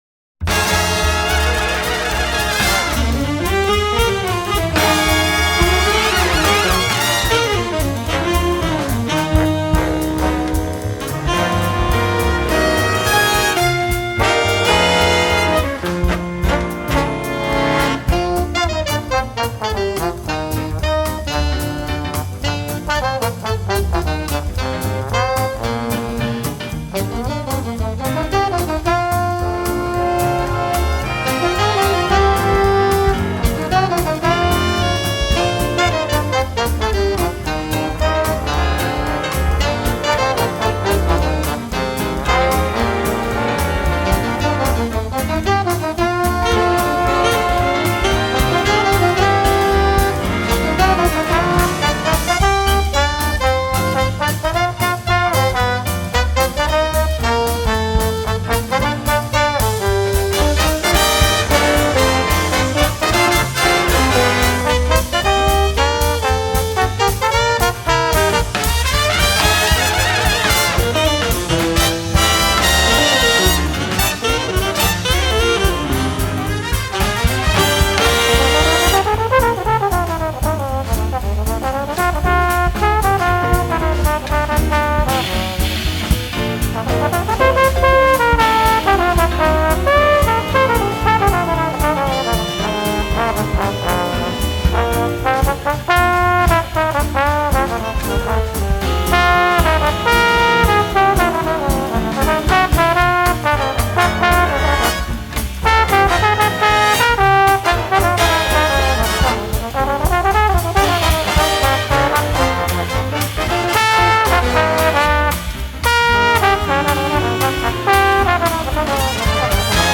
Big band
Ballad to Latin to Medium swing to Fast swing